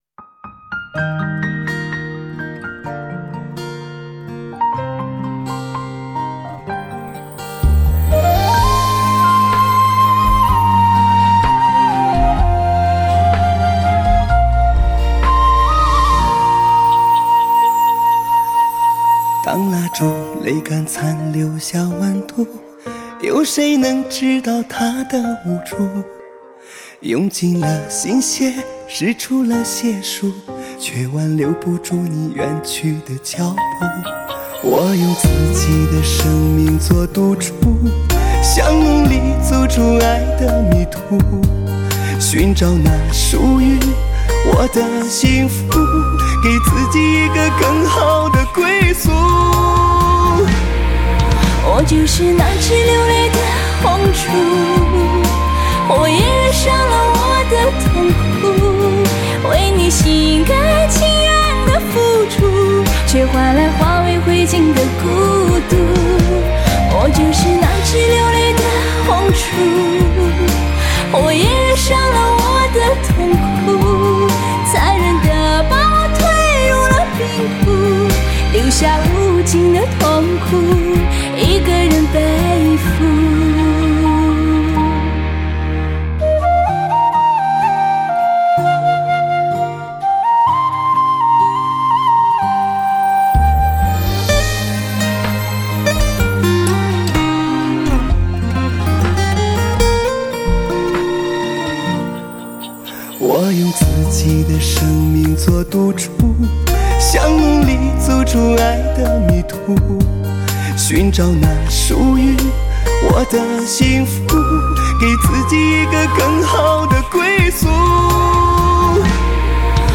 深情的对唱：《好男女全世界宣布爱你》[wav][bd]